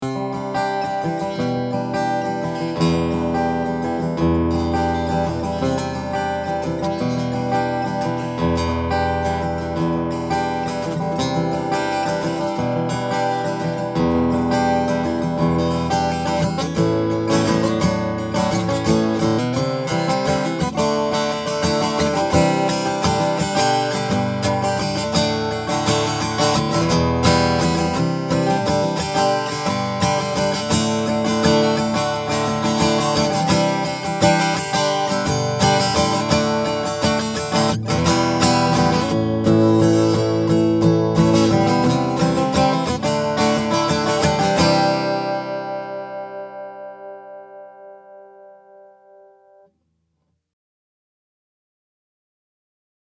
All of the clips are with the guitar plugged directly into my pre-amp going into my DAW.
I recorded the individual images with the image mix cranked all the way up.